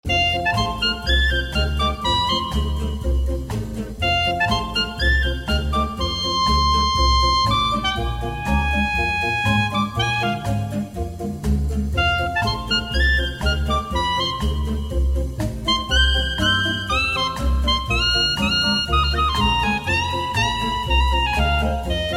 • Качество: 128, Stereo
веселые
без слов
инструментальные
Флейта